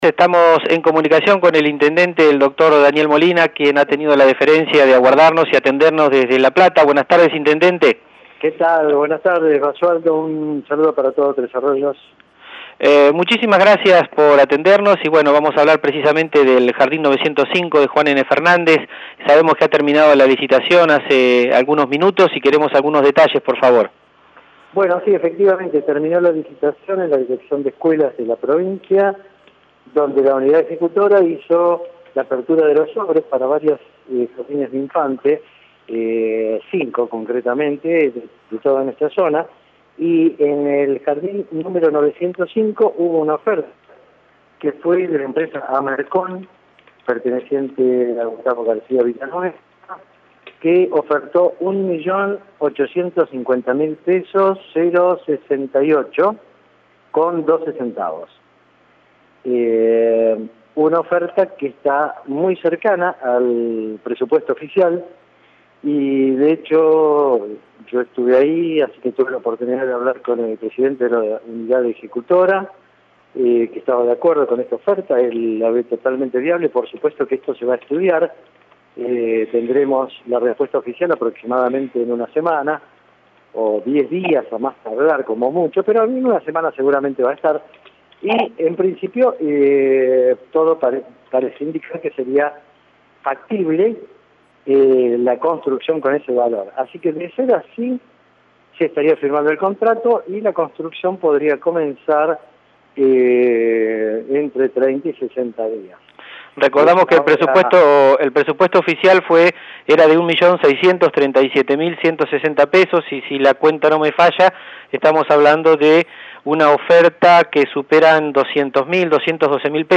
En declaraciones exclusivas para LU 24 y JNFNet, apenas concluido el acto, Molina dijo que esta leve diferencia del presupuesto oficial con la única oferta no debiera ser un obstáculo para la construcción del edificio.
Escuchar nota con el Intendente Molina